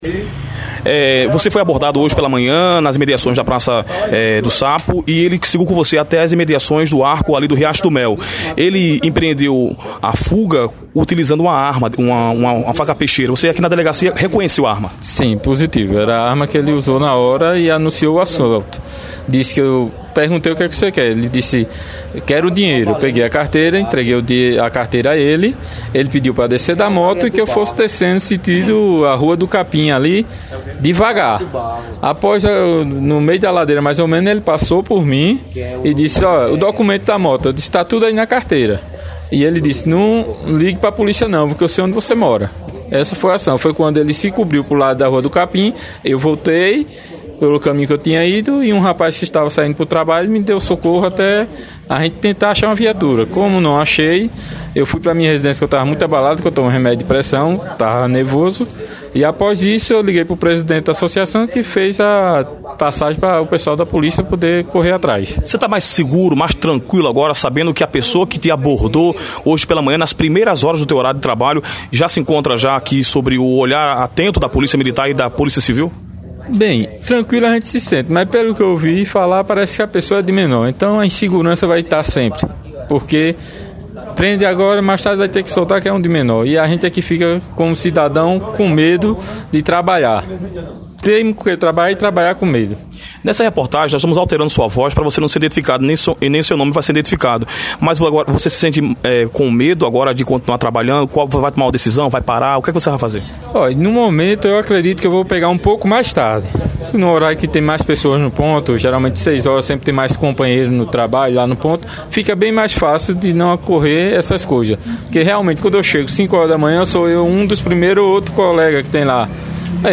A vítima concedeu entrevista exclusiva e deu detalhes de como tudo aconteceu. Ele disse que ficou abalado, mudará sua rotina de trabalho e se sente inseguro pelo fato do menor supostamente ser posto em liberdade.
ENTREVISTA-MOTOTAXI.mp3